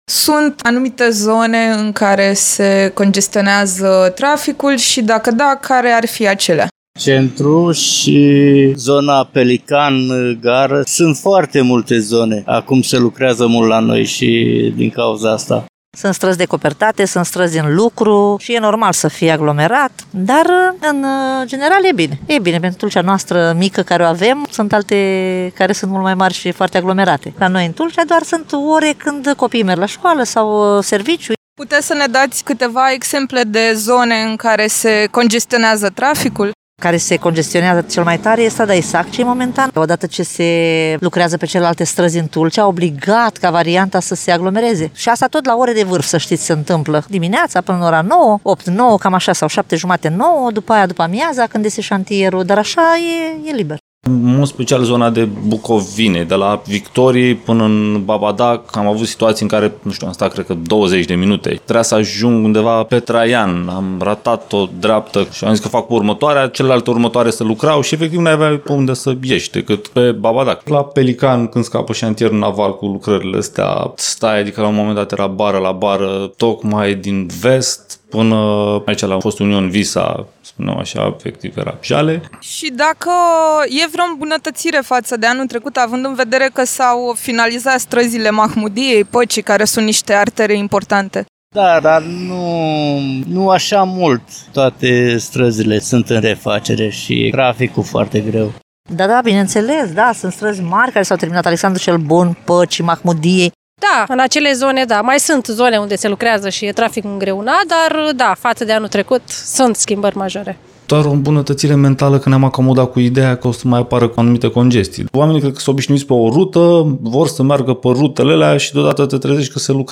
Unii șoferi vorbesc despre blocaje în centru și în zona Pelican, dar remarcă și îmbunătățiri.